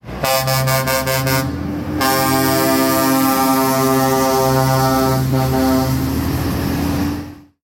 Klaxon Trompe Nedking 95 CM chromée 120 décibels
Trompe de diamètre 180 MM et puissance 120 décibels.
Son de la Trompe Nedking 95CM chromée (cliquez / tapez sur la phrase).
son-trompe-nedking-95-cm-klaxon-120-decibels.mp3